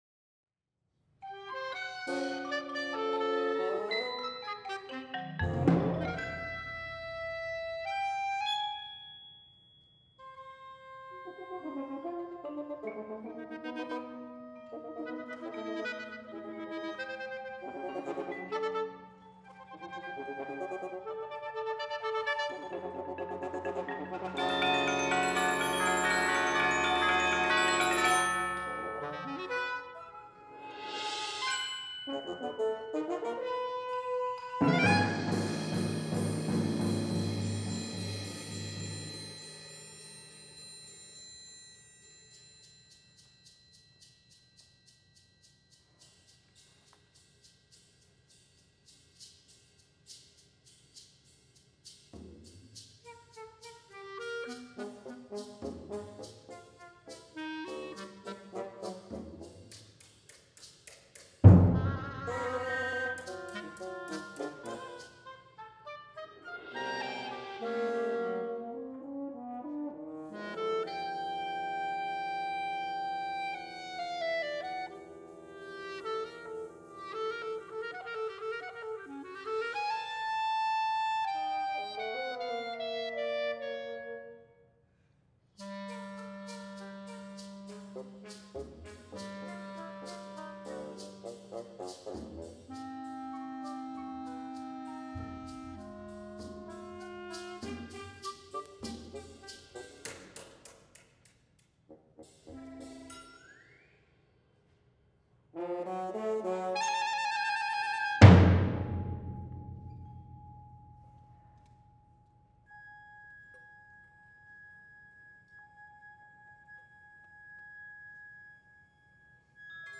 for mixed ensemble